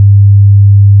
下面几个信号的音调感觉起来跟y是一样的，只是音色不同。
(100Hz)